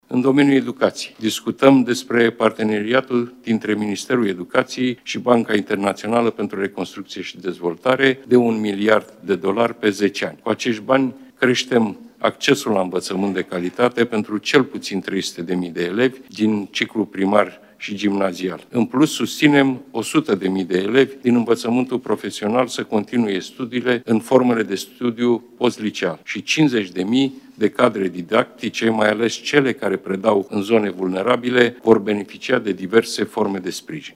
La începutul ședinței de Guvern de astăzi, premierul a mai spus că România va împrumuta un miliard de dolari de la Banca de Reconstrucție și Dezvoltare pentru investiții în educație și ajutoare pentru cadrele didactice care merg să predea în zone defavorizate.